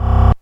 描述：FM向下投手
Tag: FM收音机 合成器 低音 模块化